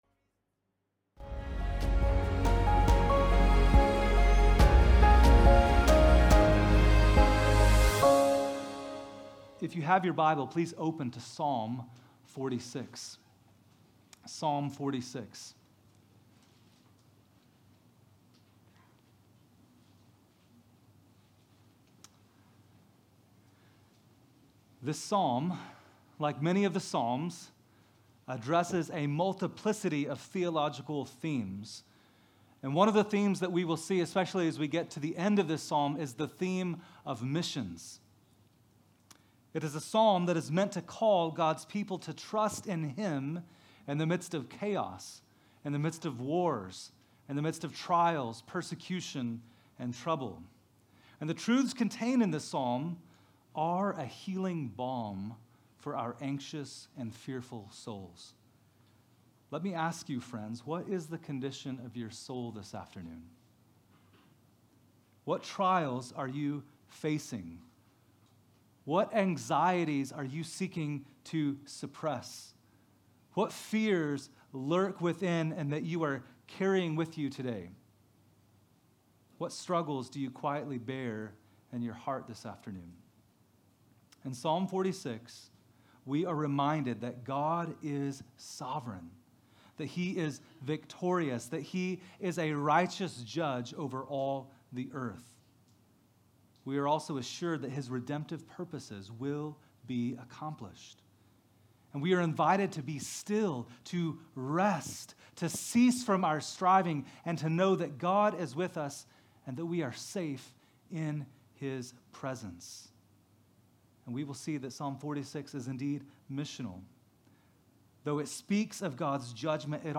Sermons Podcast - Be Still and Know that I am God | Free Listening on Podbean App